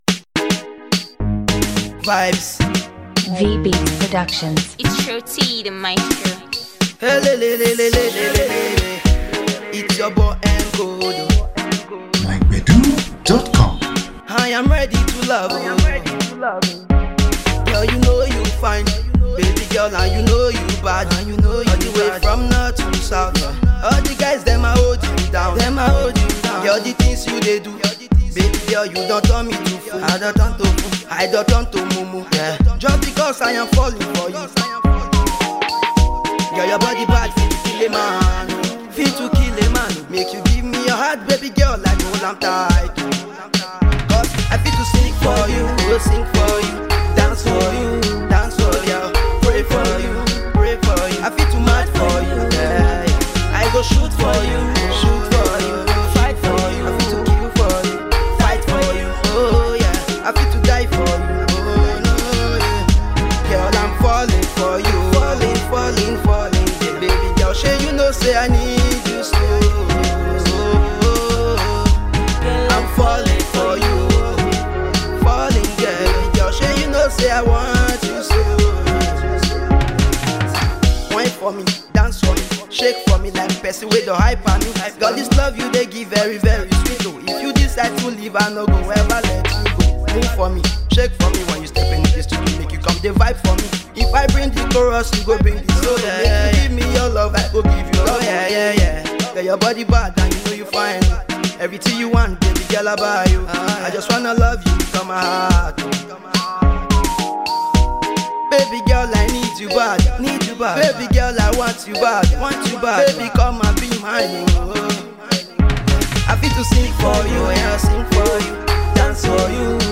The still got the vibe to put you up on your feet.